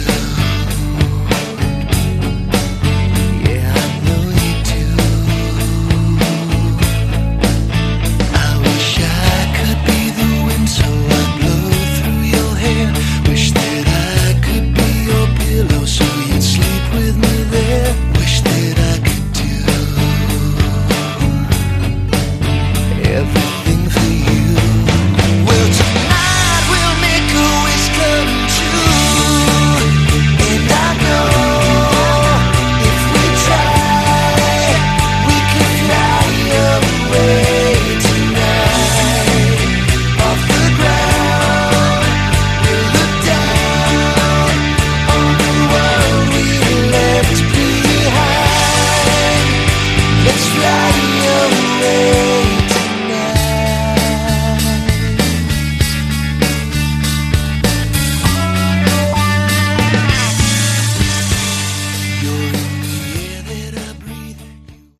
Category: Melodic Rock/Aor
lead vocals
guitars, bass, vocals
drums, keys, vocals